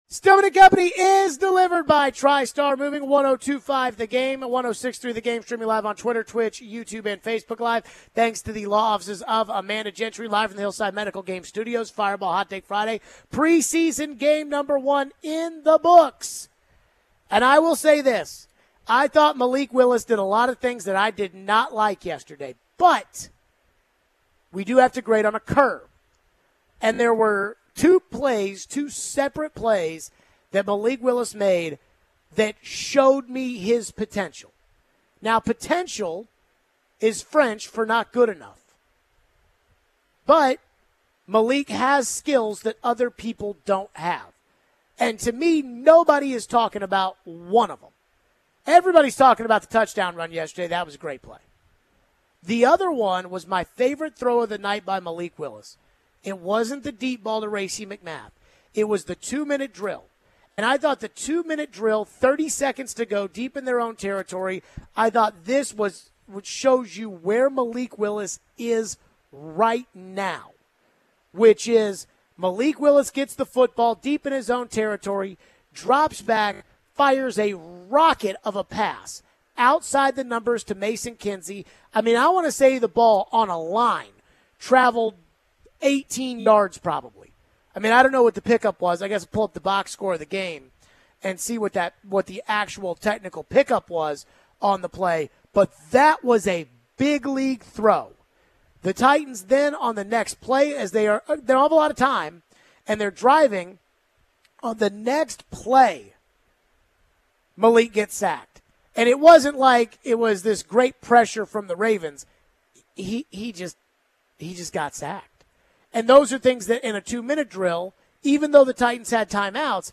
We get back into Woodside and Willis and take your calls. Which of the younger players did we want to see more from?